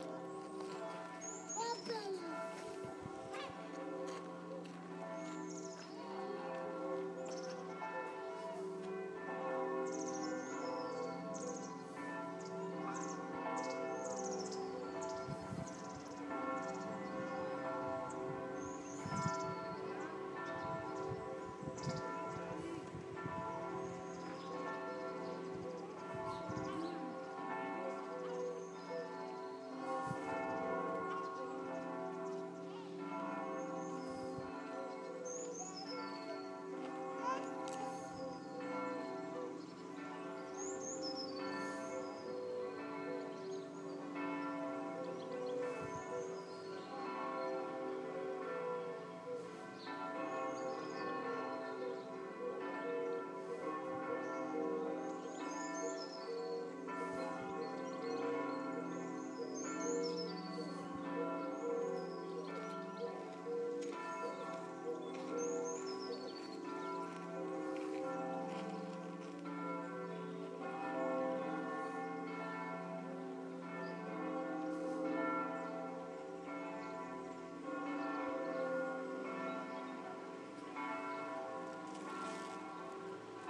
Campanas Catedral Girona desde Sant Pere de Galligants